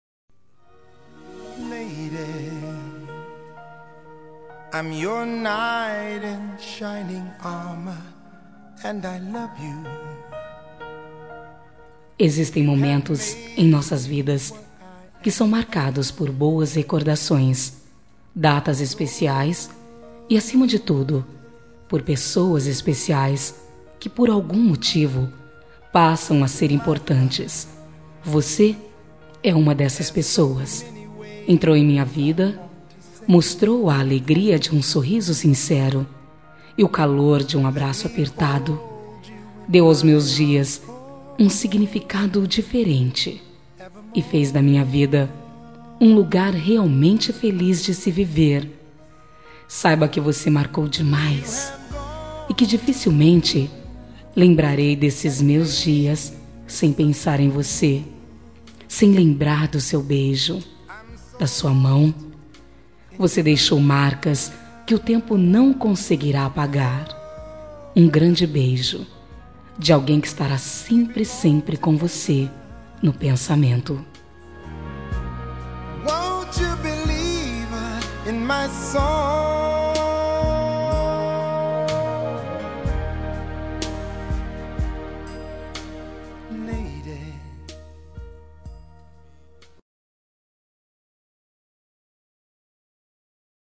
Telemensagem Romântica para Ex. – Você marcou demais Voz Feminina